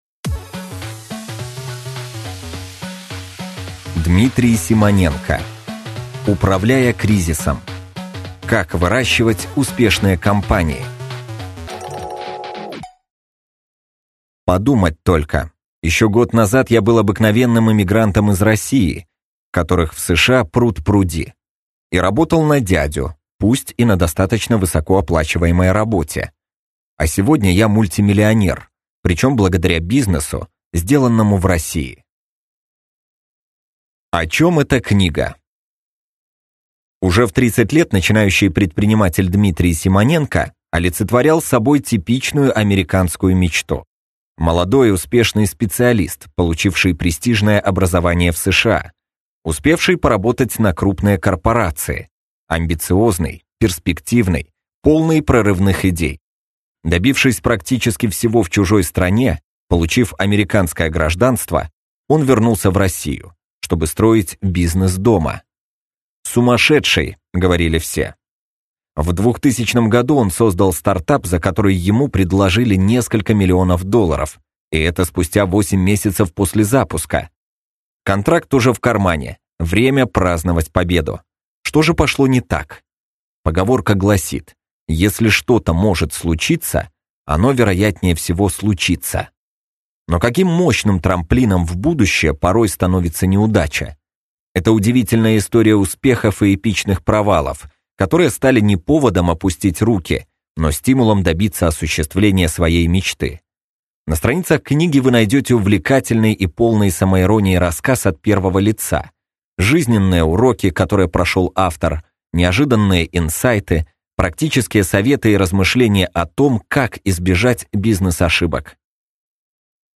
Аудиокнига Управляя кризисом. Как выращивать успешные компании | Библиотека аудиокниг